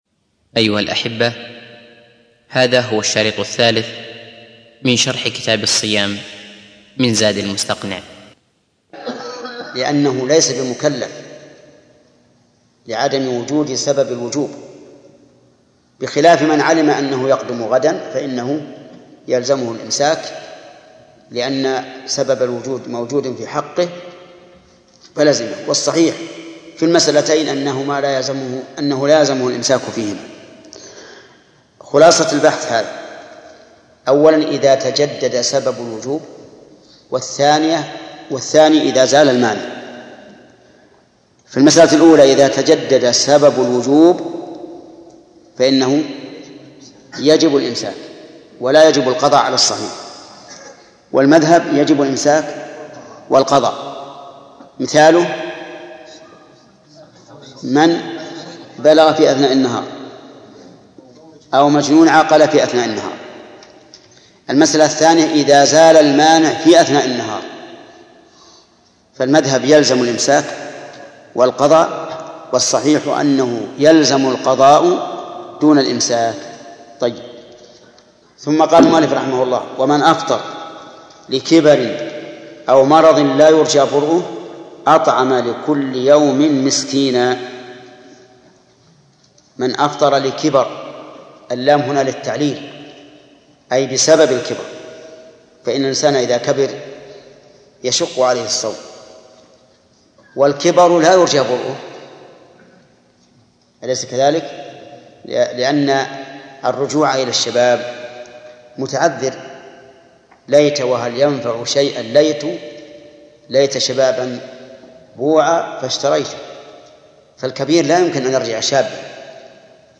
الدرس الثالث -شرح كتاب الصيام من زاد المستقنع - فضيلة الشيخ محمد بن صالح العثيمين رحمه الله